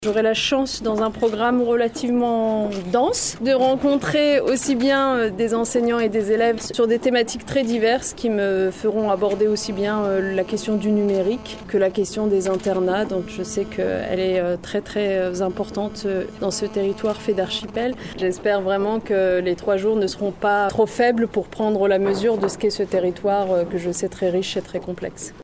La ministre de l’Education Nationale, Najat Vallaud-Belkacem, a été accueillie jeudi soir à l’aéroport de Tahiti Faa’a par les autorités du Pays et de l’Etat.